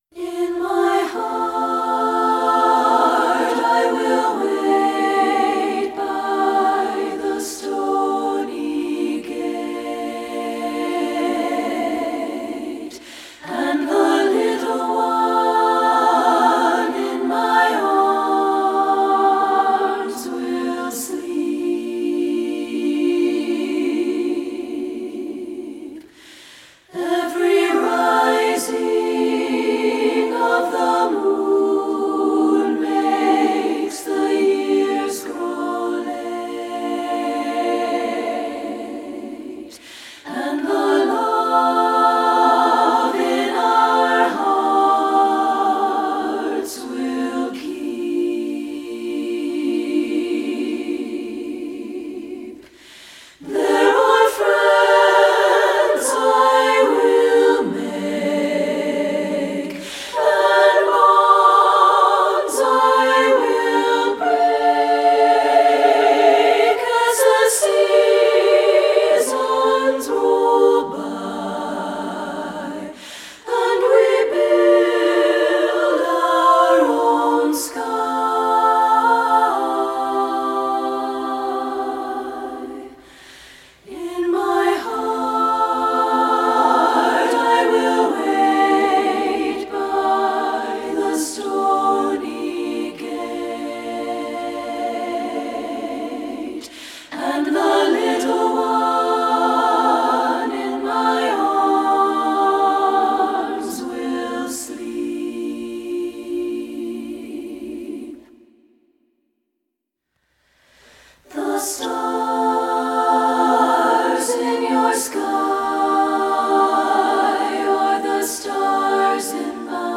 Voicing: SSA a cappella